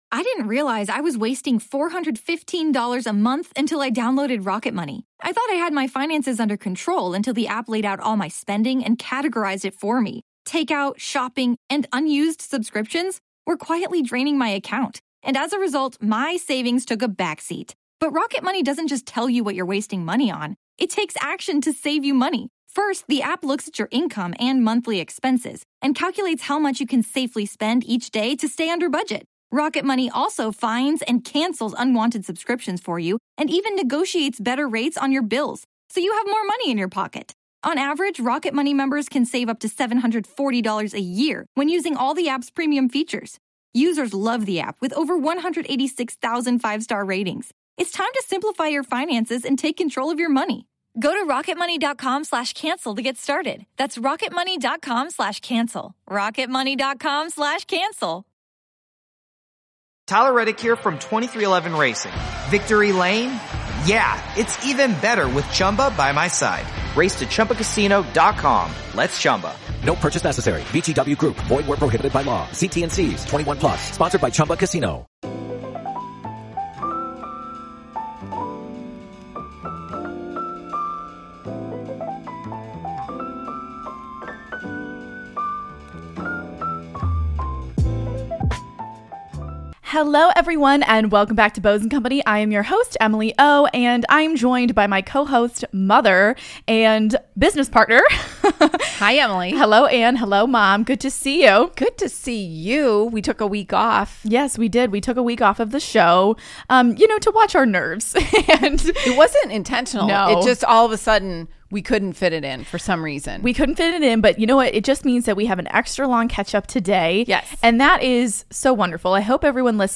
It's a relaxed and enjoyable conversation between friends!